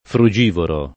[ fru J& voro ]